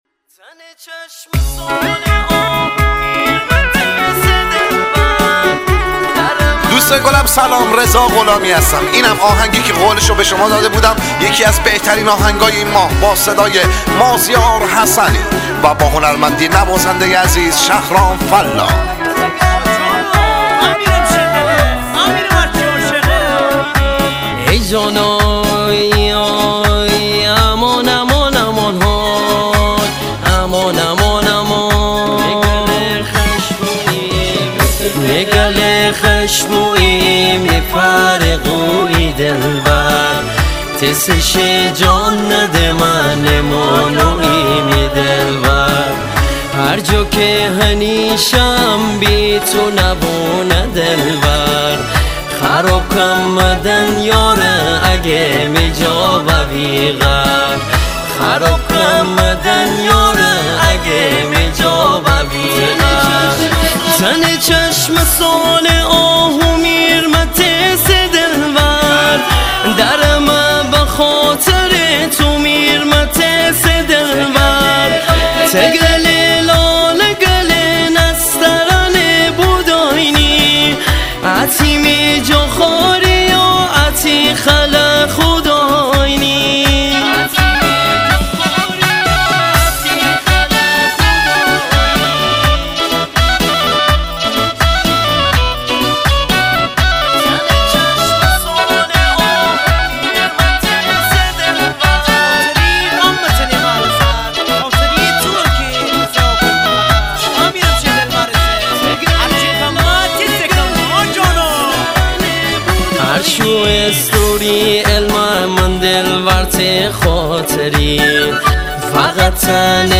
آهنگ شمالی
آهنگ گیلانی
آهنگ های شاد شمالی